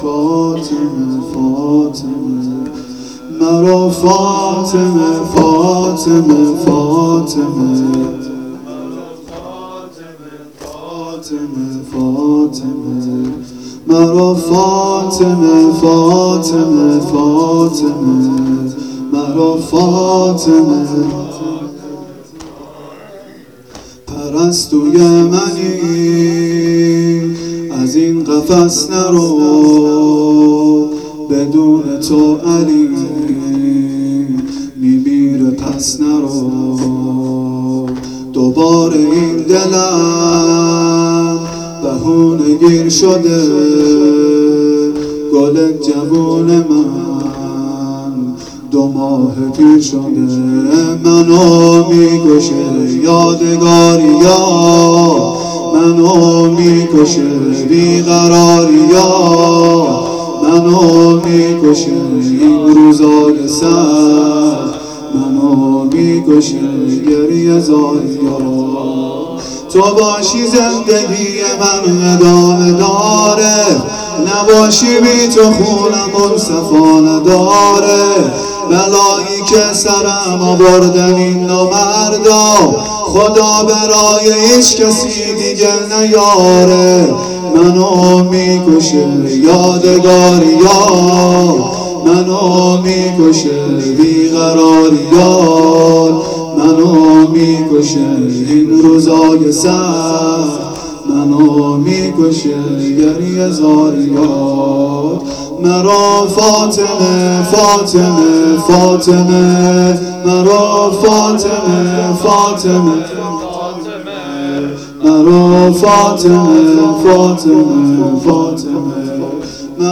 فاطمیه سینه زنی فاطمیه اشتراک برای ارسال نظر وارد شوید و یا ثبت نام کنید .